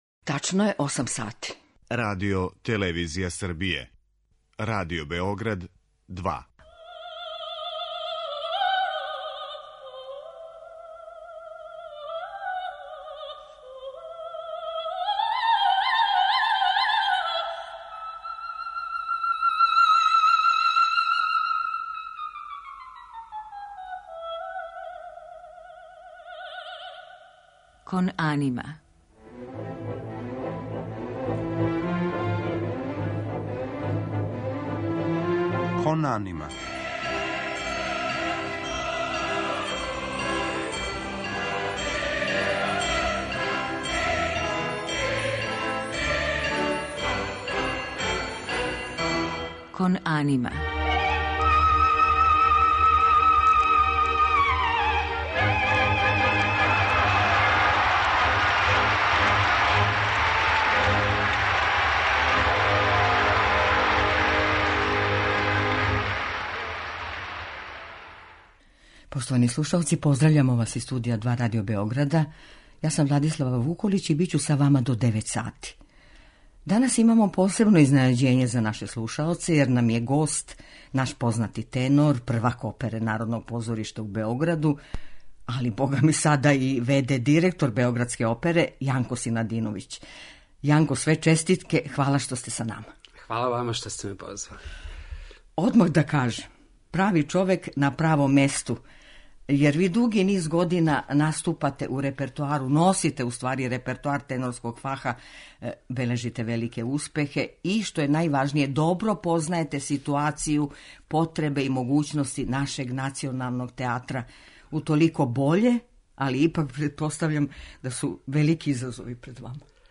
Гост у емисији је нови в.д. директор Oпере Народног позоришта у Београду, тенор Јанко Синадиновић.
У музичком делу биће емитoвани фрагменти из опера Волфганга Амадеуса Моцарта, Ђузепа Вердија и Рихарда Вагнера.